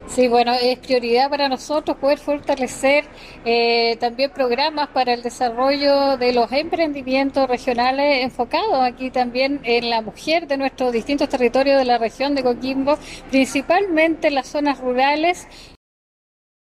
Así lo destacó la gobernadora regional, Krist Naranjo.
Krist-Naranjo-Gobernadora-.mp3